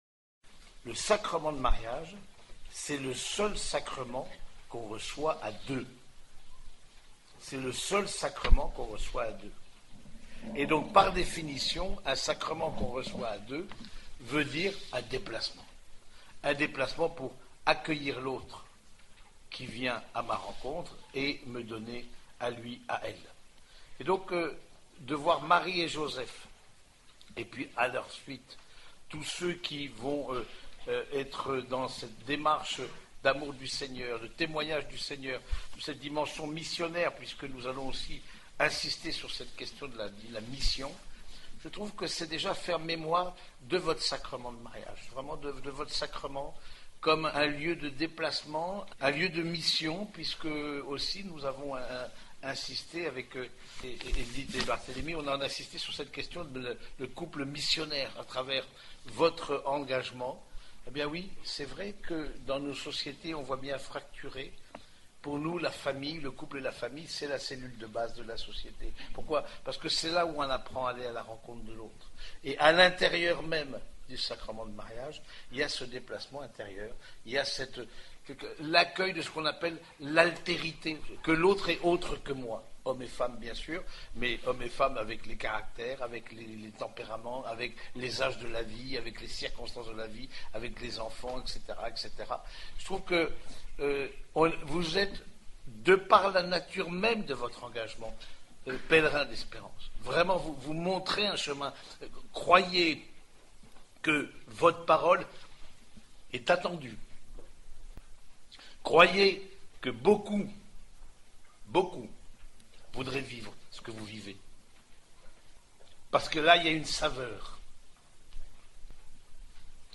Conférence donnée lors du rassemblement END province Nord Est Ile de France.